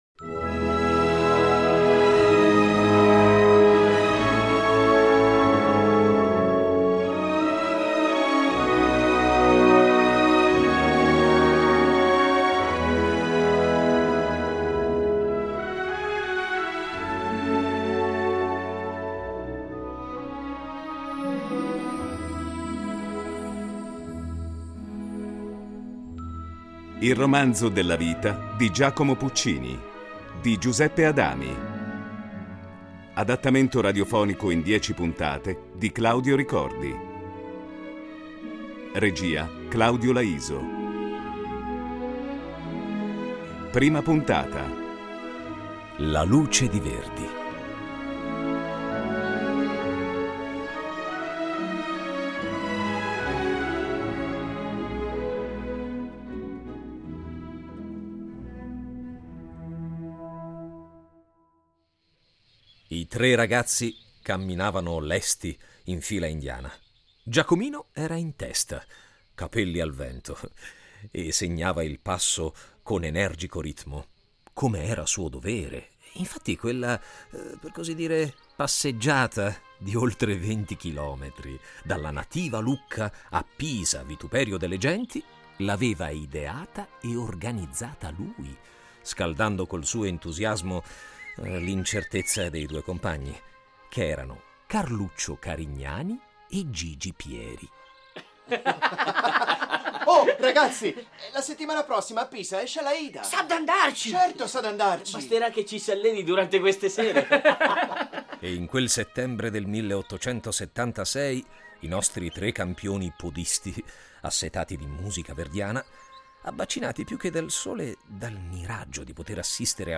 un adattamento radiofonico